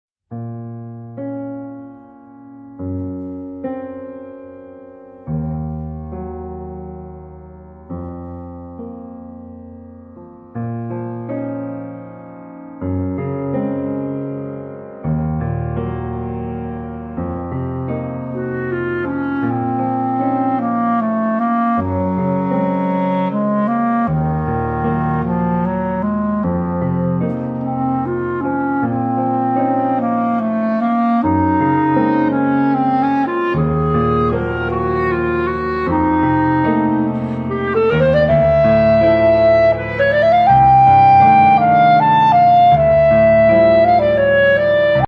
Obsazení: Klarinette und Klavier